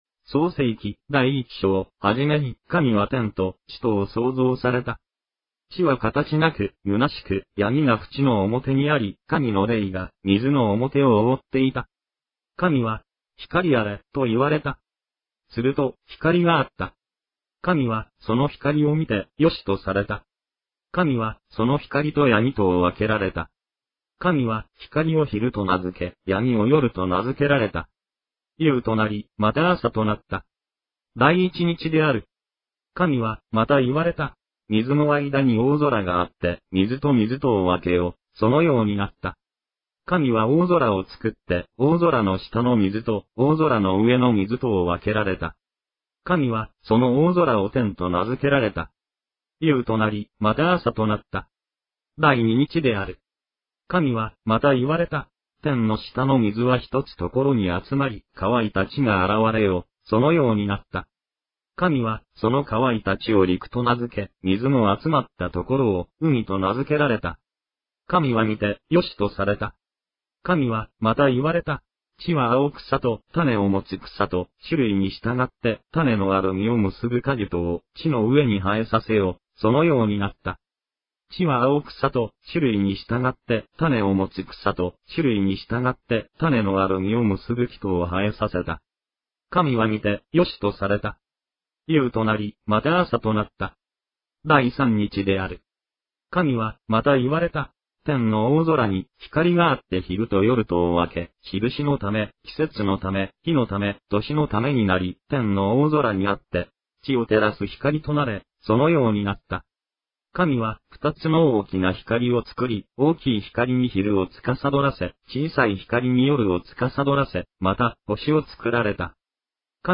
• Front view - Audio Bible Japanese Bible reader - Bible read aloud - Easy to Use